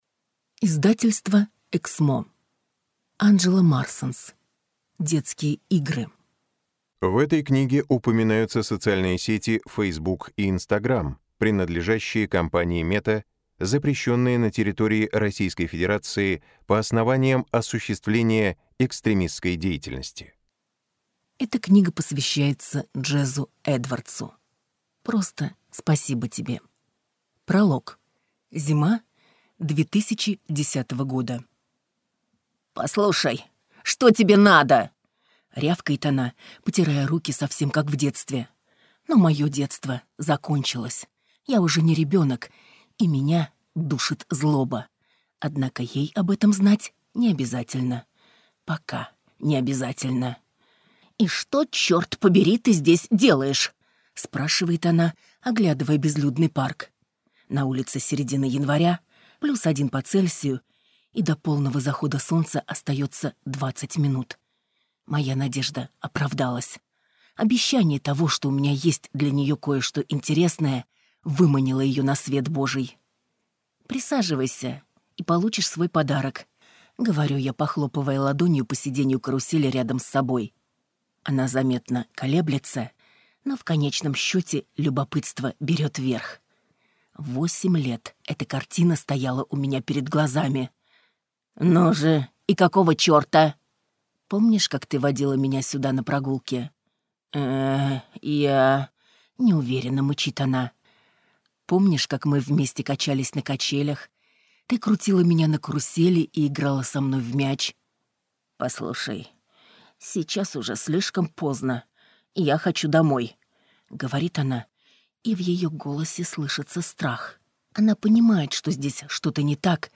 Аудиокнига Детские игры | Библиотека аудиокниг